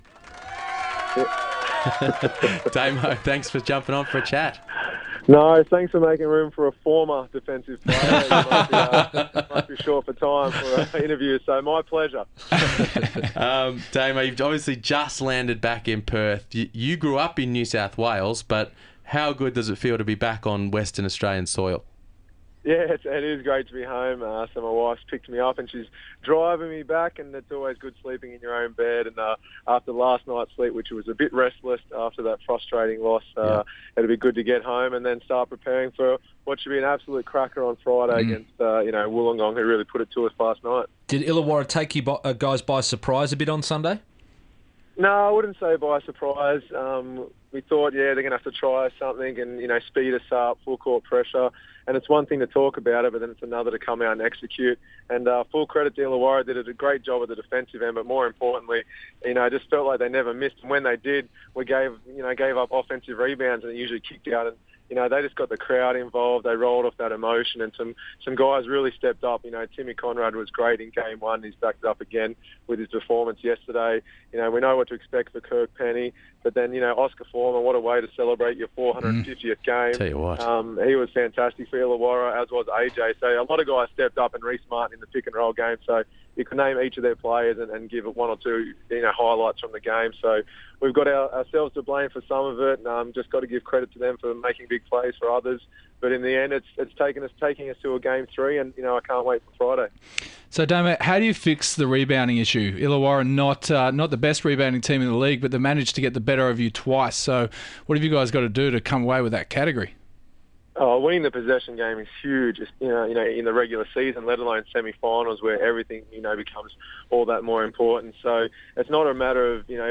Damian Martin Interview